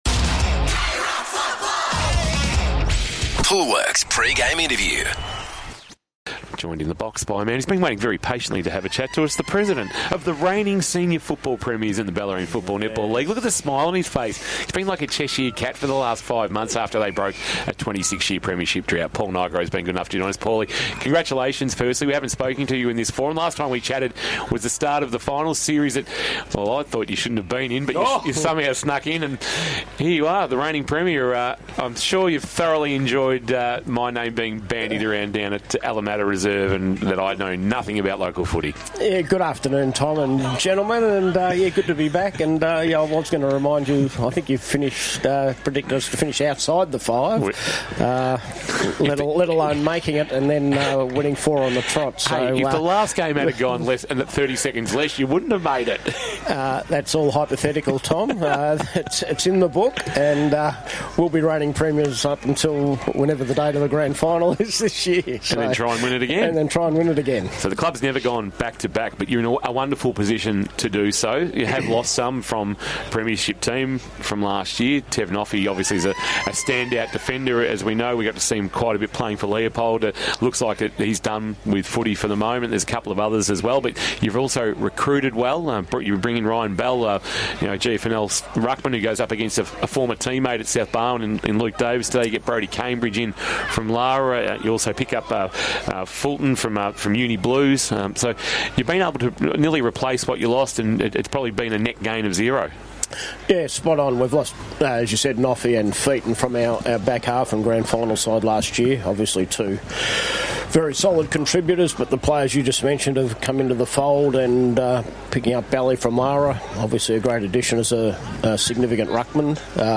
2026 - BFNL - Round 3 - Barwon Heads vs. Anglesea - Pre-match interview